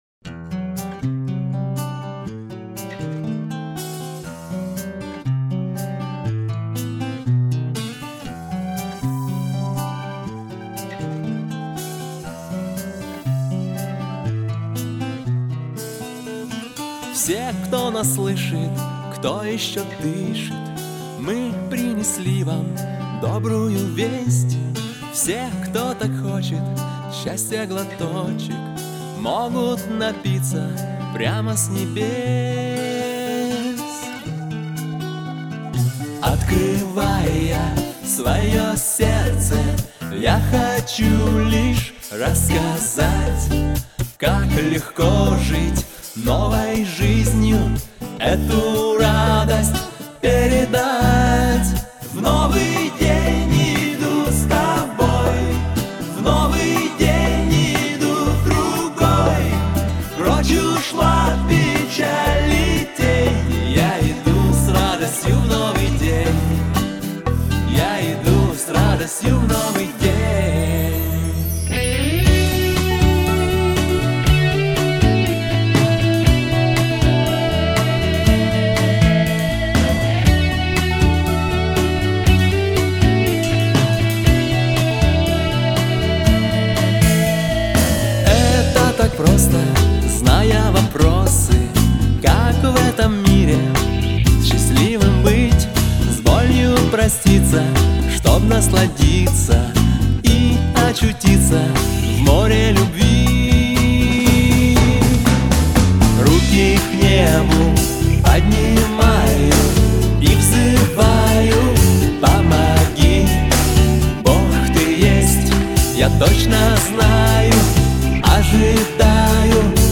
574 просмотра 433 прослушивания 33 скачивания BPM: 120